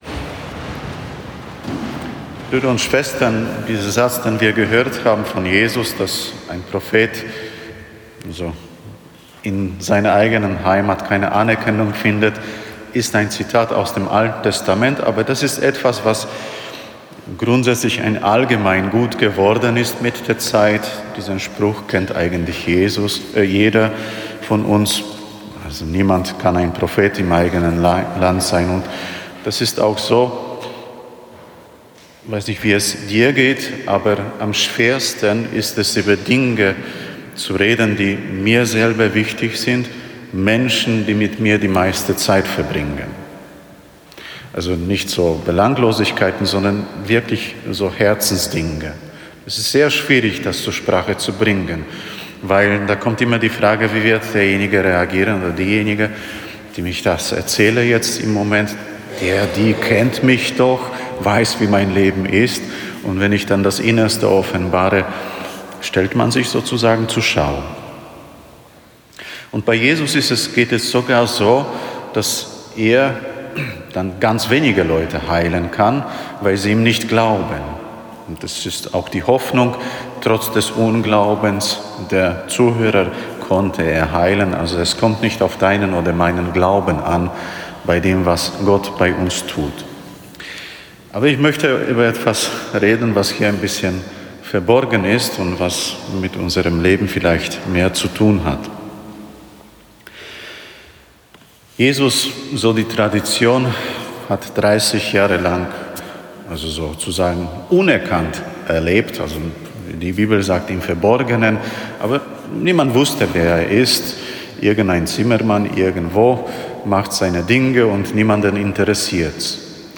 Eine Predigt zum 14. Sonntag im Jahreskreis B